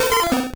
Cri de Tartard dans Pokémon Or et Argent.